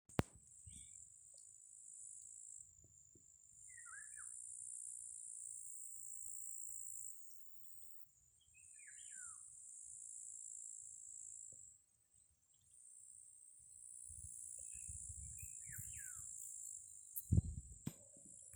Vālodze, Oriolus oriolus
Ziņotāja saglabāts vietas nosaukumsSkandīne
StatussDzied ligzdošanai piemērotā biotopā (D)